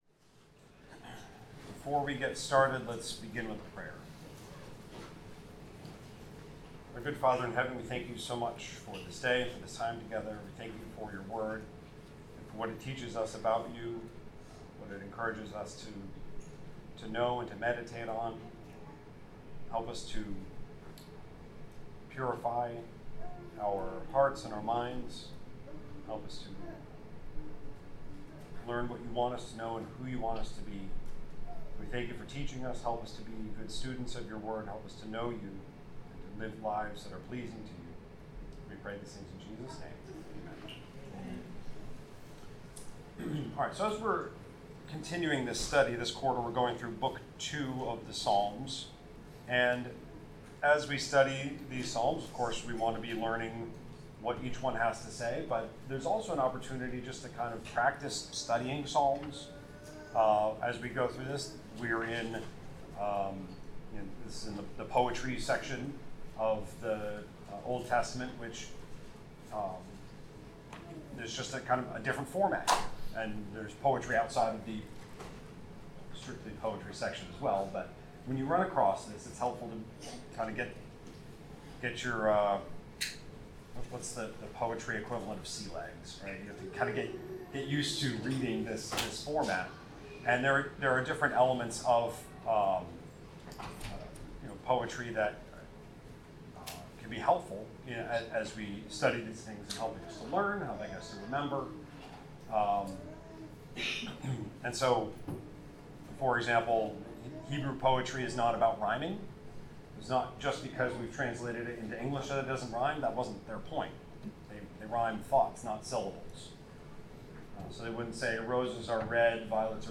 Bible class: Psalms 46-48
Service Type: Bible Class